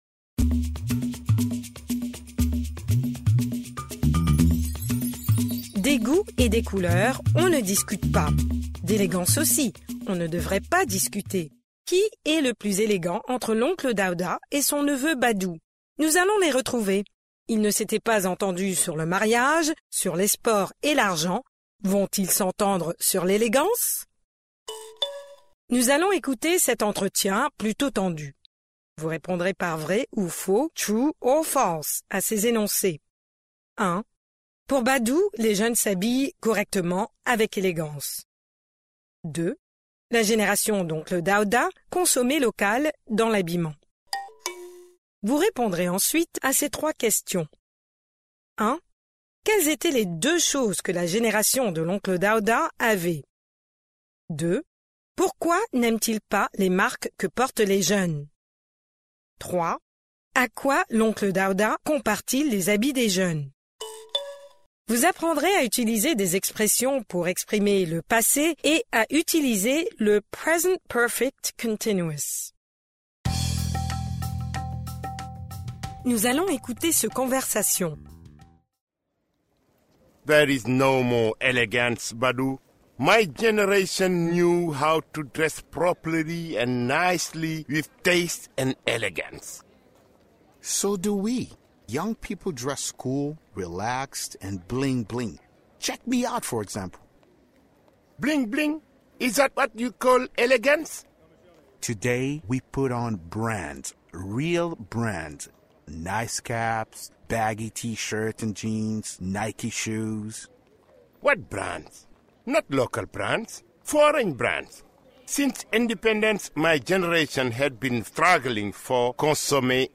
Pre-listening: Nous allons écouter cet entretien, plutôt tendu !